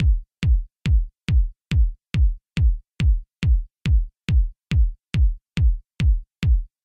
Step 1 – Choose a Kick Drum
For this style of music the Roland TR808 and TR909 drum machines were really popular, so I have chosen a nice meaty 909 kick. I’ve also set the tempo of the track to a healthy 140 BPM.